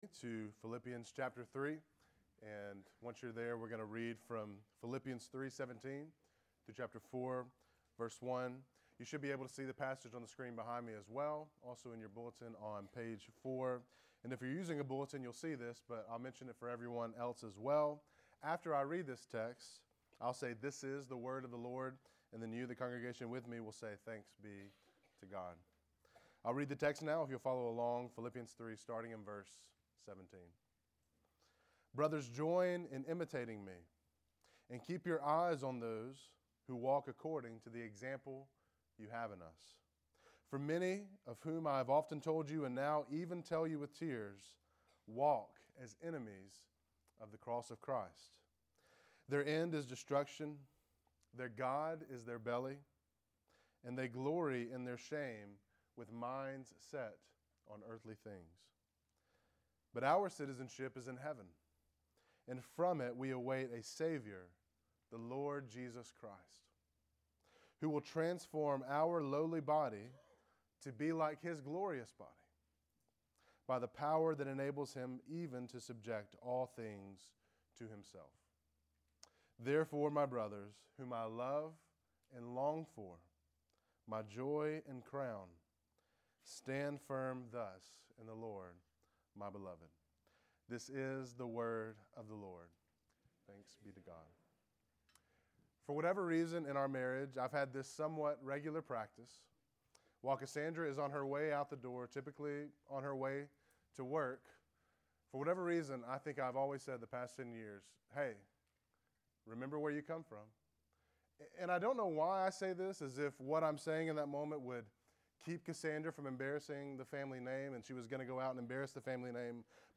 Sermons | Grace Bible Church of Oxford